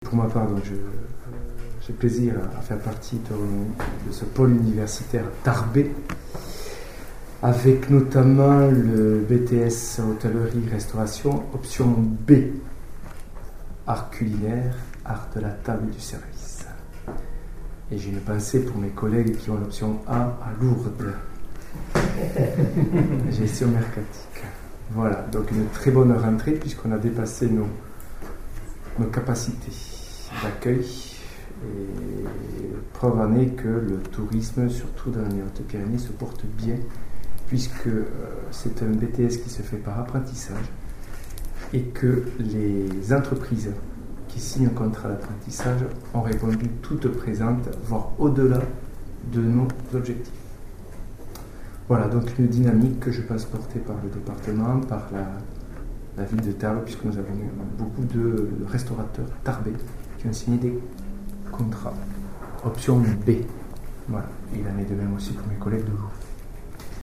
Chaque responsable d’établissement est ensuite intervenu.
Les interventions